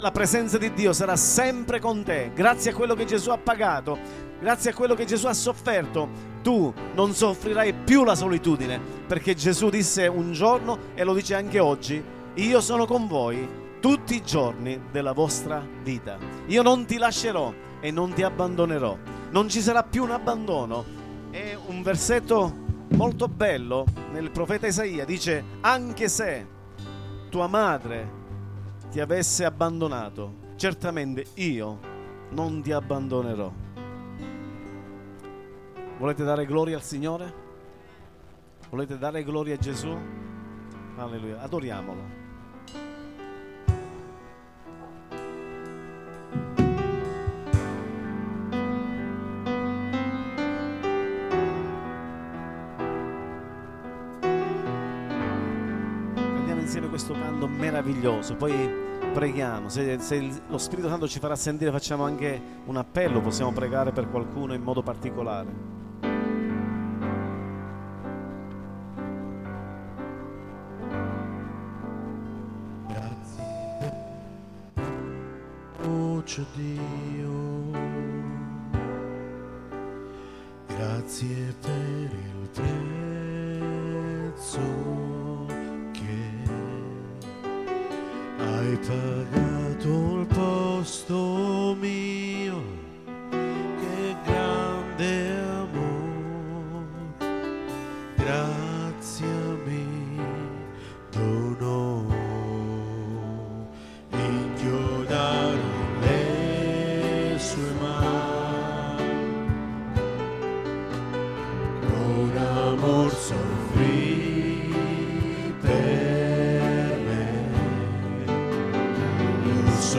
momento di adorazione...